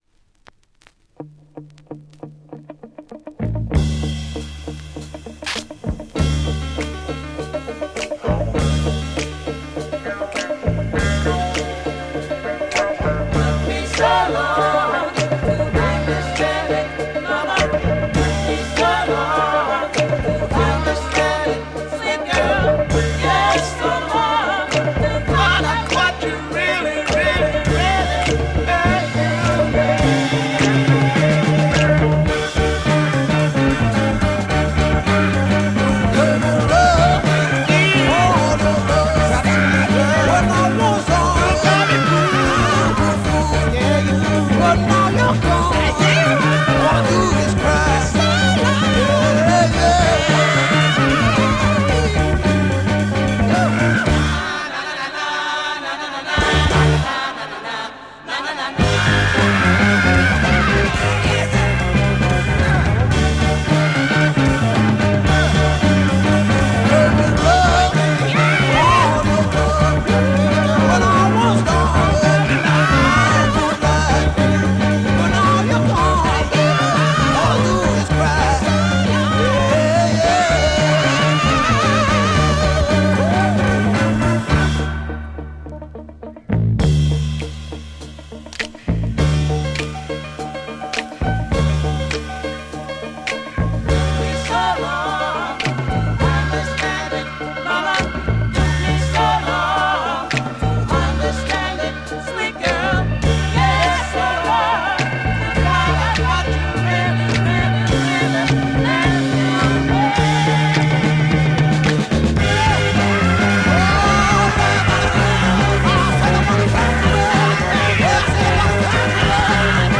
Rare Euro Freakbeat Mod single